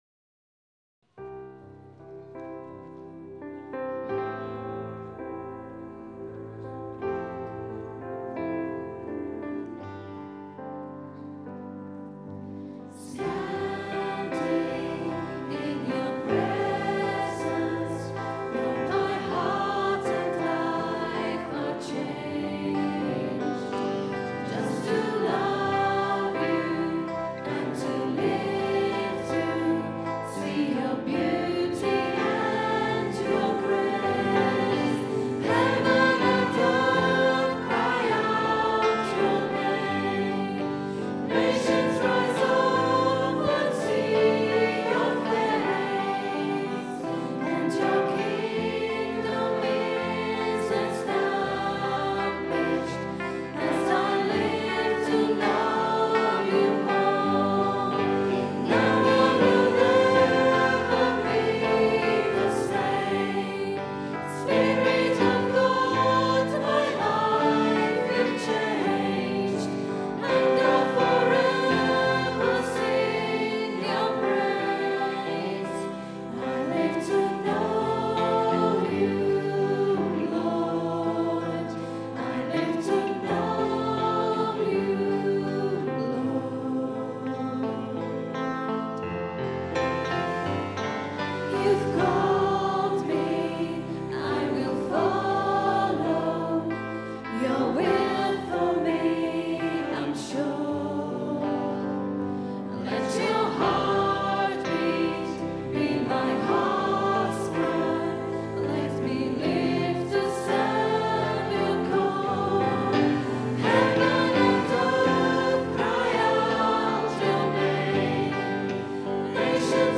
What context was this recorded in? Recorded on a Zoom H4 digital stereo recorder at 10am Mass Sunday 27th June 2010.